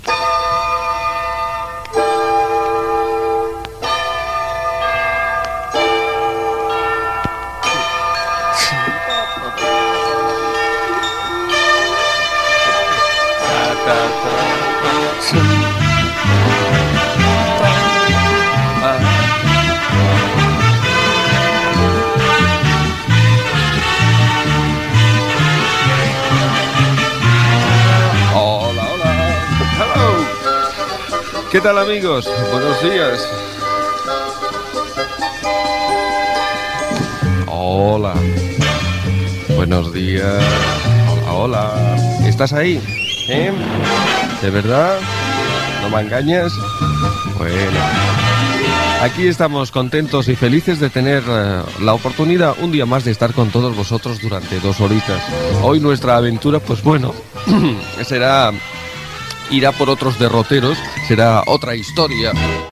Sintonia i començament del programa
Entreteniment